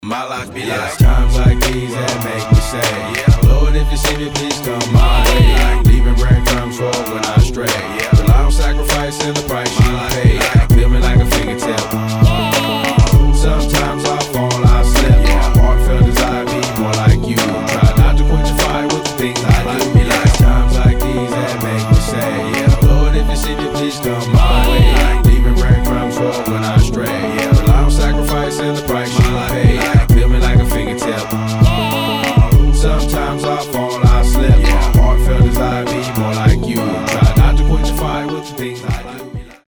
• Качество: 320, Stereo
позитивные
забавный голос
beats
христианский рэп
Christian Hip hop